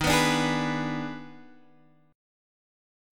EbM7sus4#5 chord